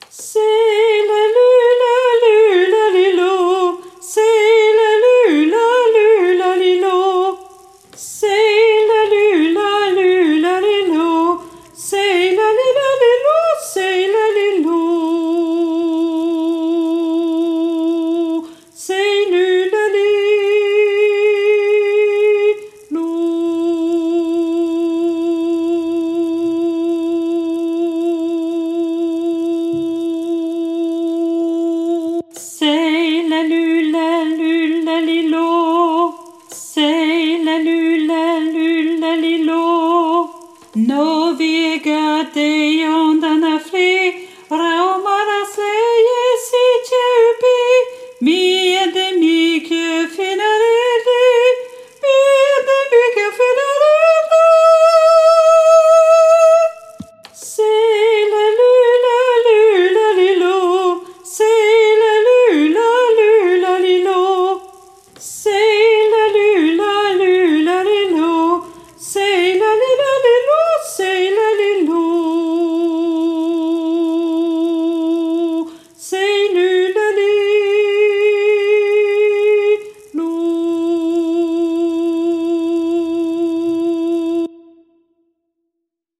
Alto 1